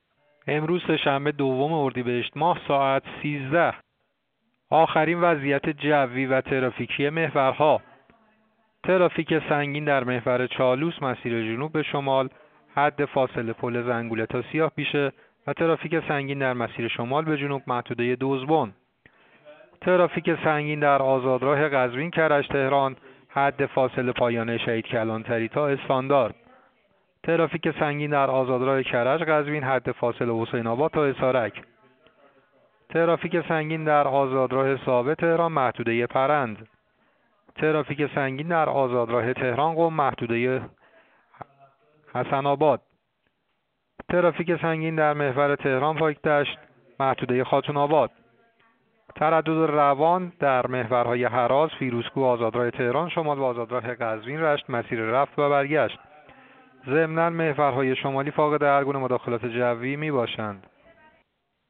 گزارش رادیو اینترنتی از آخرین وضعیت ترافیکی جاده‌ها ساعت ۱۳ دوم اردیبهشت؛